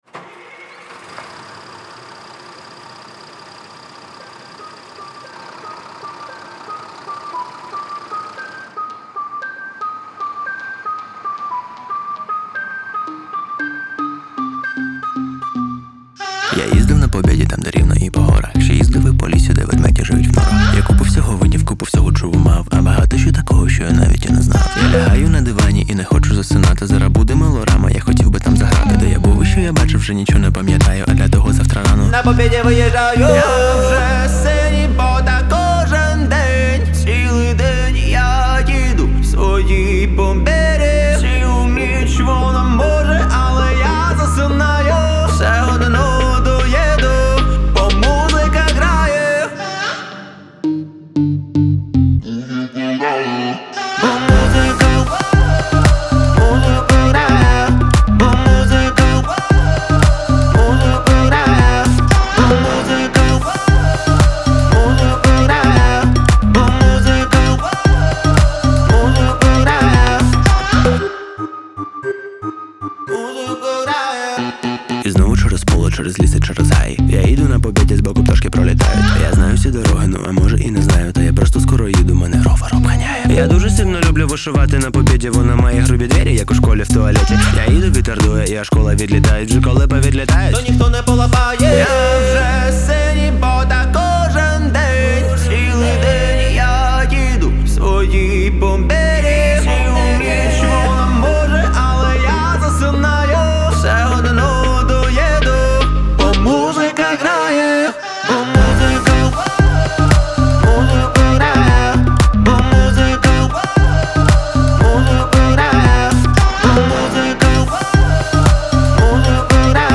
• Жанр: Pop, Electronic